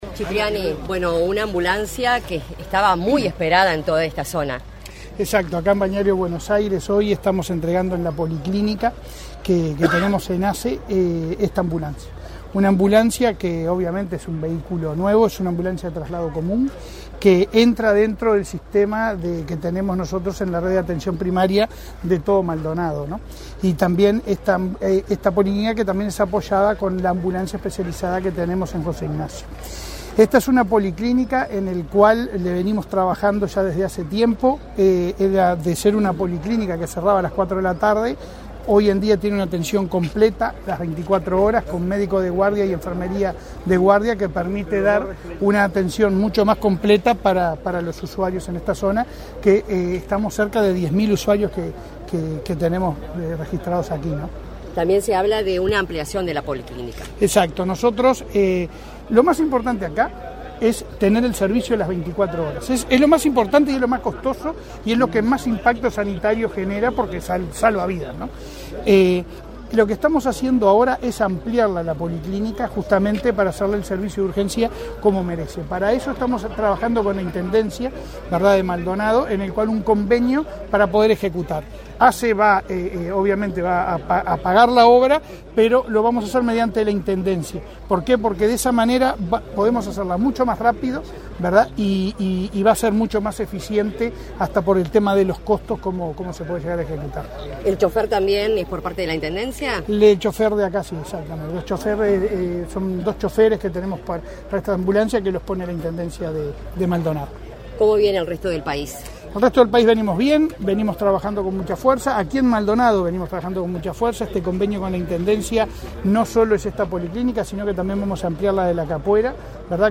Declaraciones a la prensa del presidente de ASSE, Leonardo Cipriani
Tras el evento, el presidente de ASSE, Leonardo Cipriani, realizó declaraciones a la prensa.